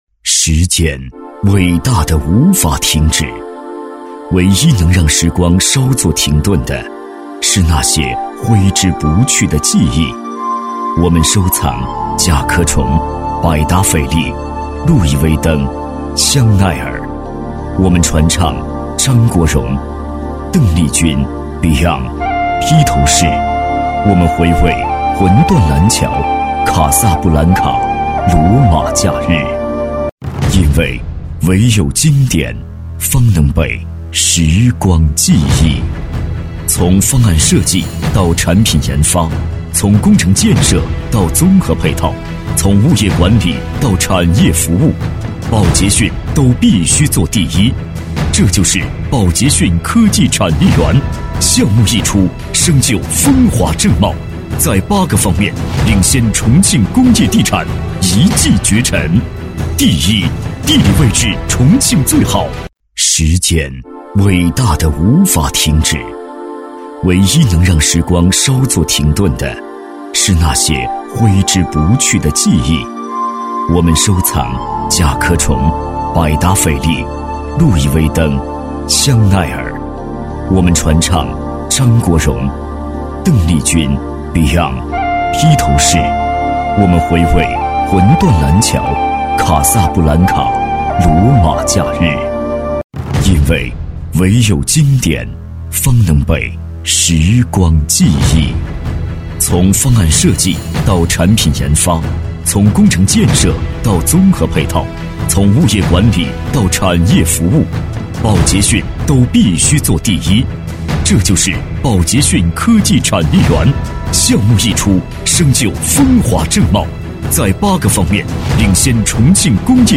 职业配音员全职配音员浑厚大气
• 男S353 国语 男声 广告-宝捷讯项目-房地产广告-沉稳大气 大气浑厚磁性|科技感|积极向上